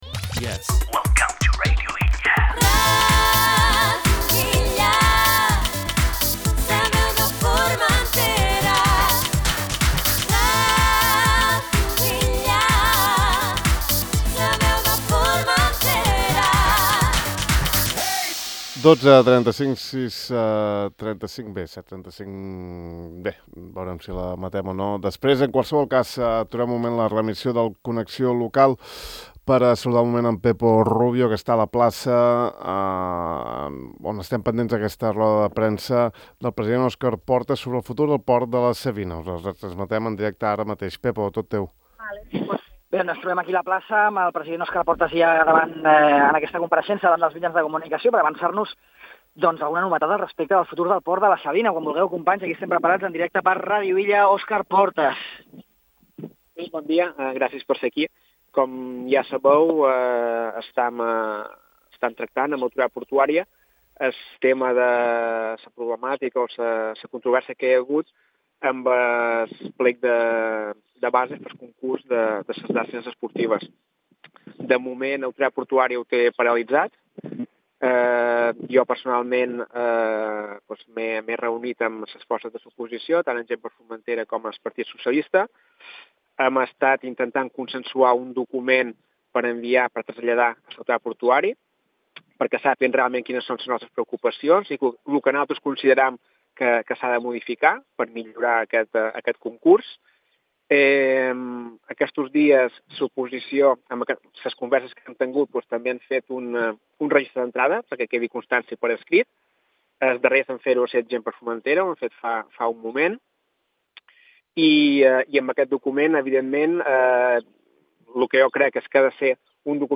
Òscar Portas, avui a la plaça.
En una roda de premsa celebrada avui a la plaça (escoltau àudio), el cap insular ha explicat que en les darreres setmanes s’ha treballat en un document per remetre en les pròximes hores a Autoritat Portuària amb la finalitat de traslladar les reclamacions tant de les diferents formacions polítiques com de les associacions integrades en el Consell d’Entitats.